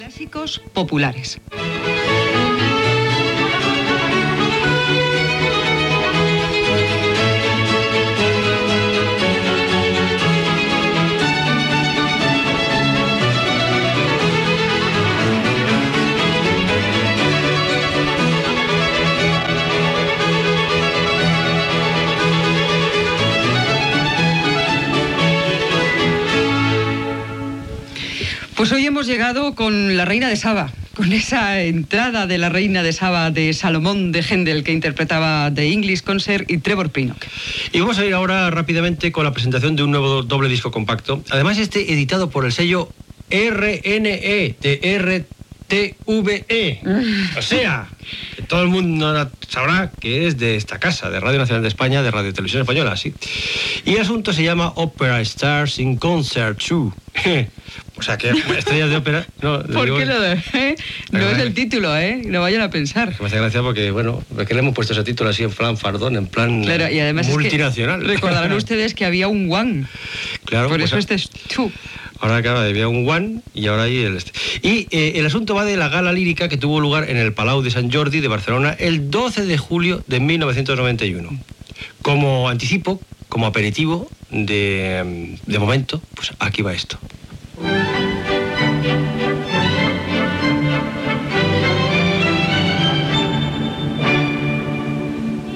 Identificació del programa i anunci d'un disc.
Musical
FM